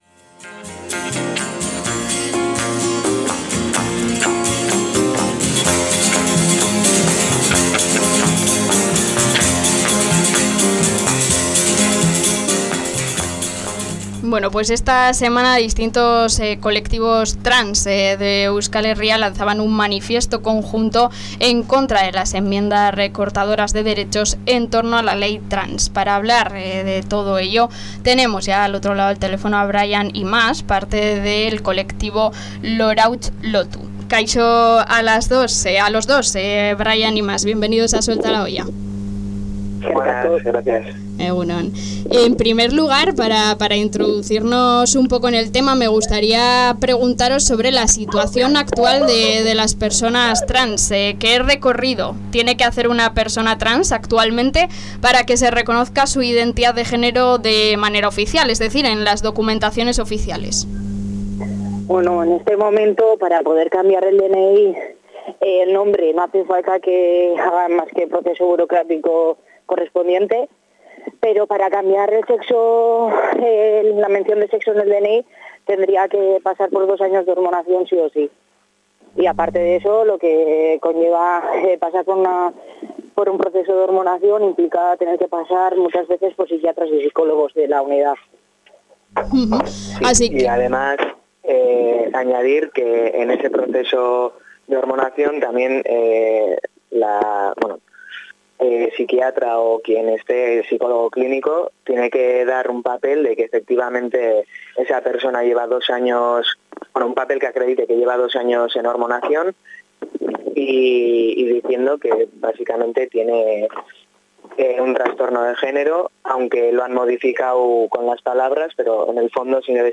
Aquí podéis escuchar la entrevista completa: